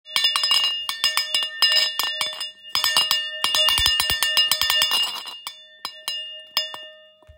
Plechový zvonek měď 11 cm
Plechový zvonek s typickým zvukem kovové kuličky zavěšené na řetízku a plechového plá&scaro